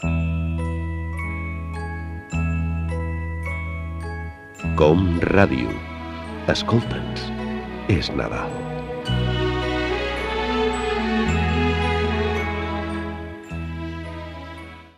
Indicatiu de Nadal